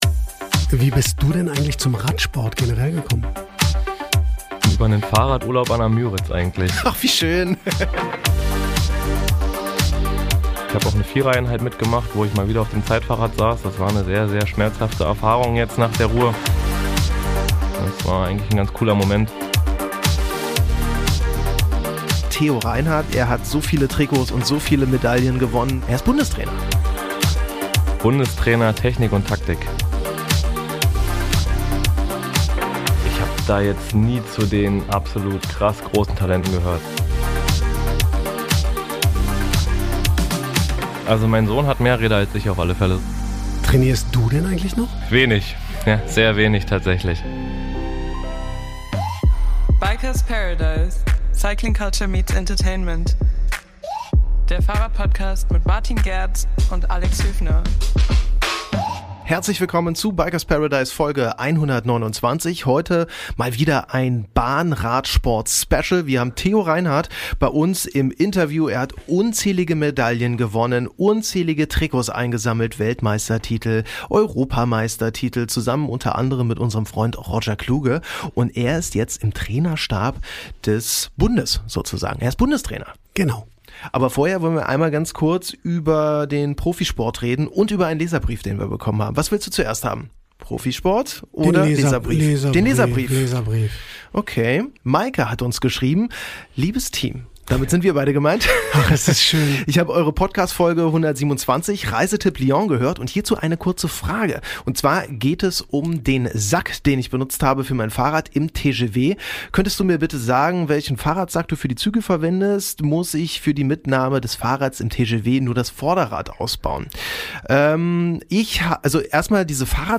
Jetzt kehrt er ins Velodrom als Bundestrainer zurück. Mit unseren Hosts redet er über seinen Sport, seine Zukunft als Bundestrainer und über Sachen für die er endlich mal Zeit hat.